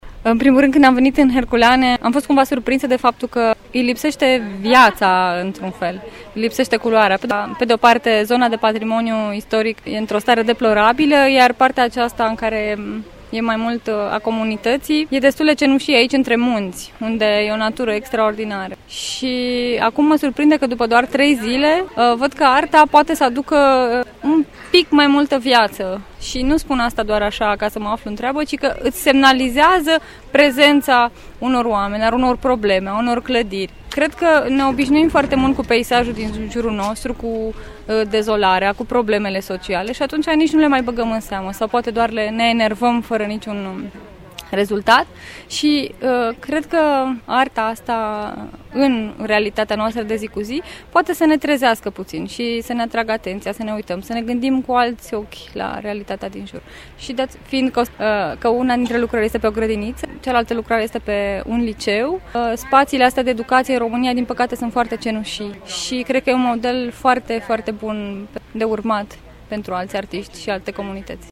La sfârșitul turului, câțiva turiști ne-au spus impresiile lor despre ceea ce au văzut că s-a lucrat în această săptămână în orașul de pe Valea Cernei :
bai-turista-Giurgiu.mp3